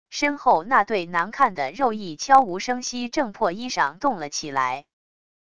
身后那对难看的肉翼悄无声息挣破衣裳动了起来wav音频生成系统WAV Audio Player